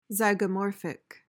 PRONUNCIATION:
(zy-guh-MOR-fik)